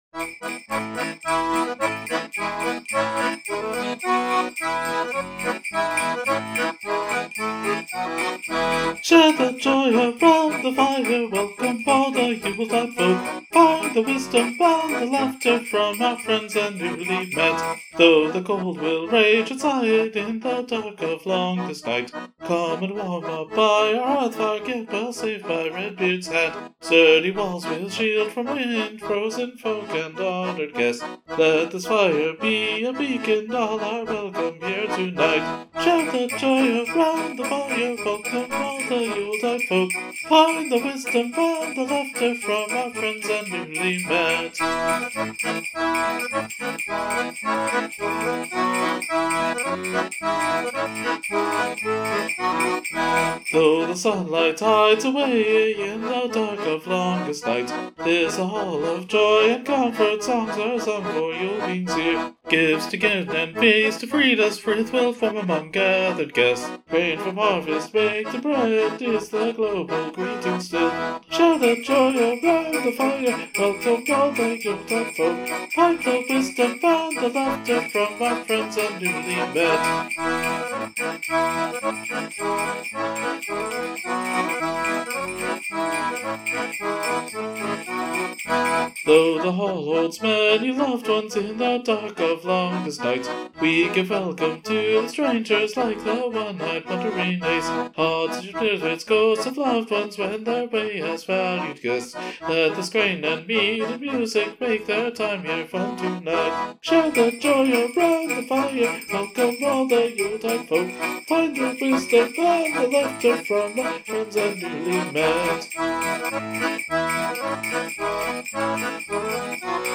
Musically, this should be pretty straightforward: 3 chords and some piece of the truth. I will mention that the biggest challenge of singing it is the syllables that flow over 2-4 notes but not much more than that, which is very easy to get a bit pitchy on.
I added some jingles because why not?